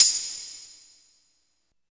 16 TAMB   -L.wav